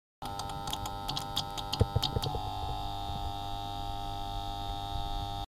Download Electronics sound effect for free.
Electronics